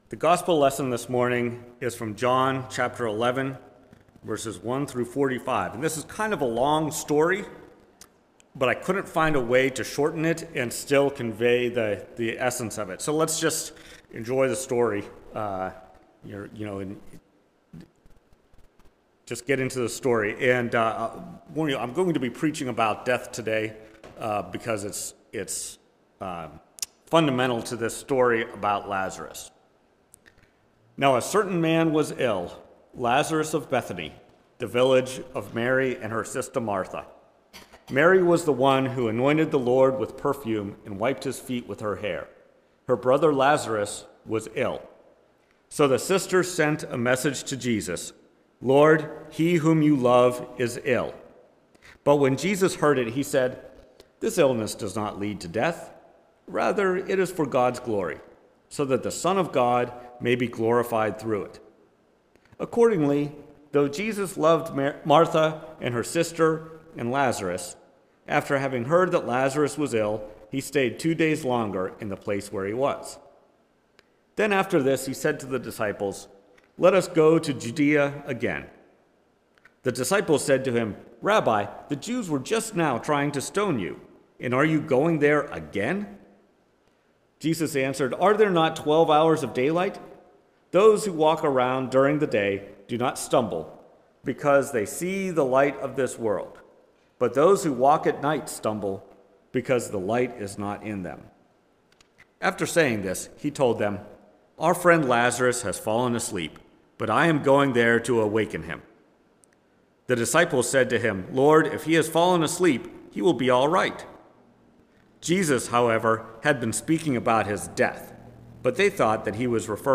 Sermon preached March 5, 2023, at First Presbyterian Church of Rolla. Based on John 3:1-17.